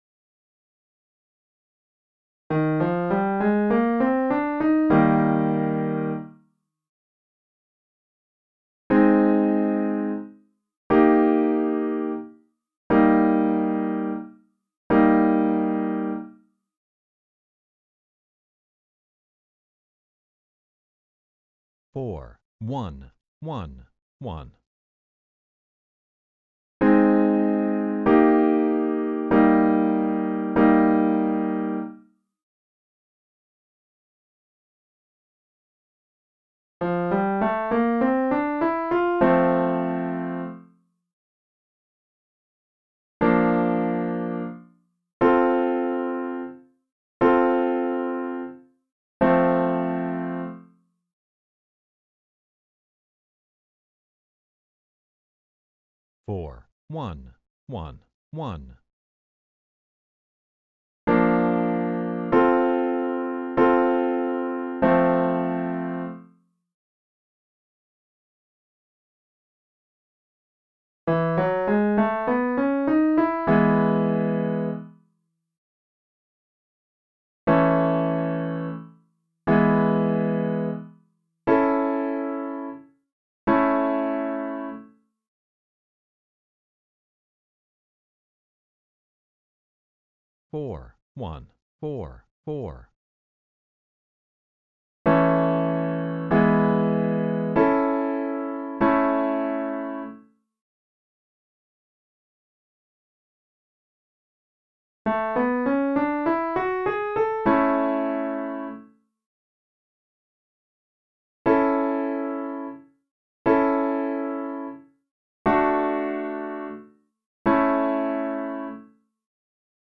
2. The “medium” exercise introduces different inversions of the chords, which means the notes can change without it actually changing chord in the progression.
Once you think you’re getting a sense of the chords, listen to the corresponding “Test” tracks, which include a short pause after each progression.
You’ll hear the correct answer so you know if you got it right and have the chance to hear the progression again.
We’ll start off with just two chords: the I and IV.